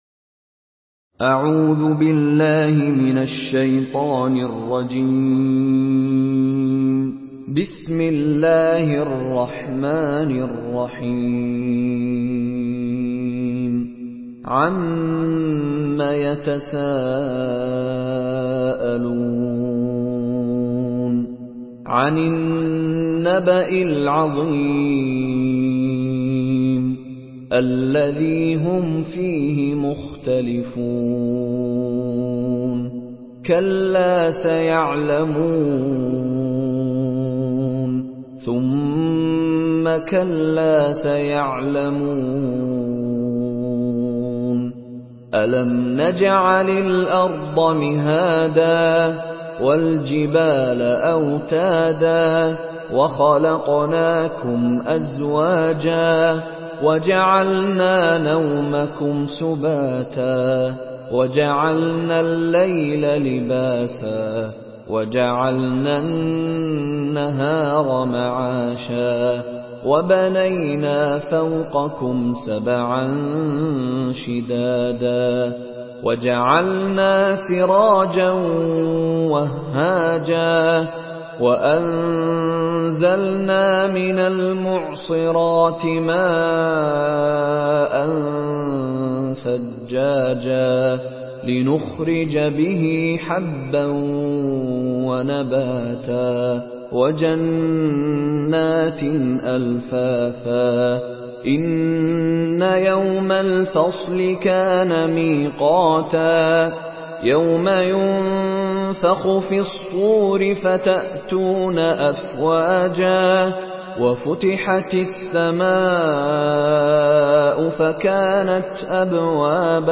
Surah আন-নাবা MP3 by Mishary Rashid Alafasy in Hafs An Asim narration.
Murattal Hafs An Asim